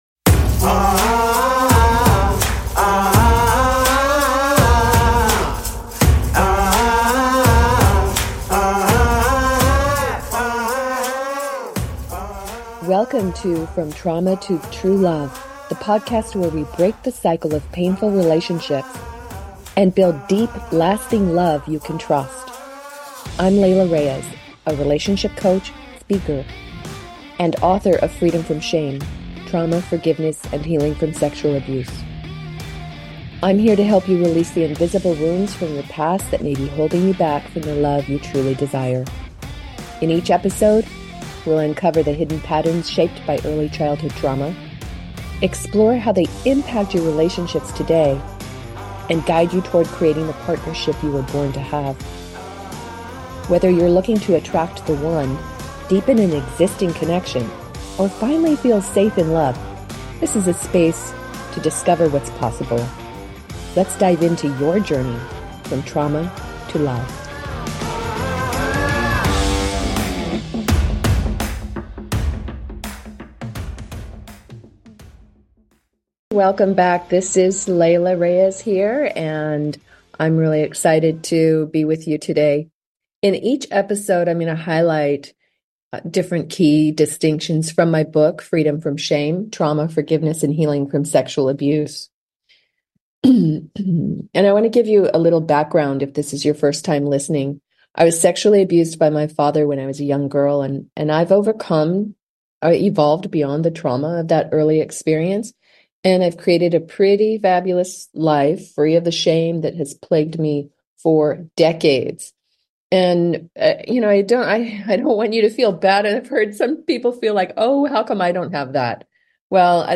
Talk Show Episode, Audio Podcast, From Trauma To True Love and S1E13, Evolving Beyond The Impact Of Child Sexual Abuse on , show guests , about Evolving Beyond the Impact of Child Sexual Abuse, categorized as Health & Lifestyle,Love & Relationships,Relationship Counseling,Psychology,Emotional Health and Freedom,Mental Health,Personal Development,Self Help,Society and Culture